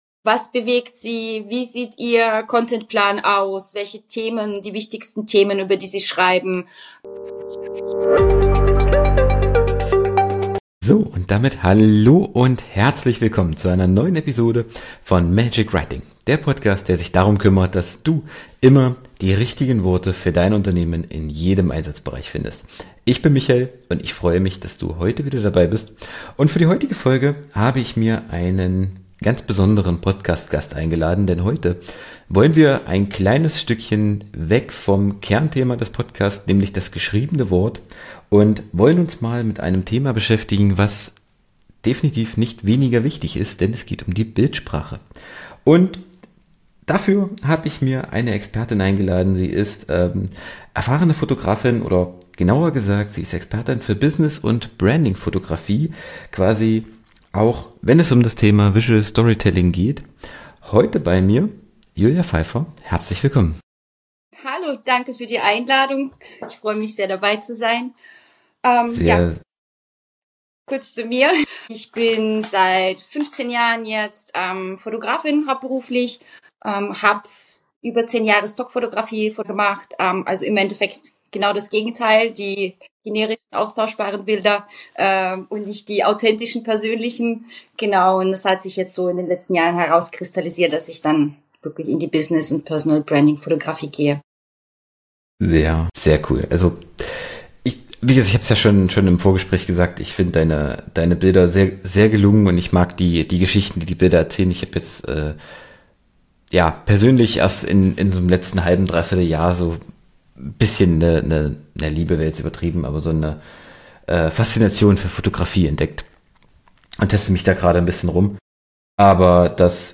Folge 308: Der Einfluss professioneller Bilder auf Dein Business - Interview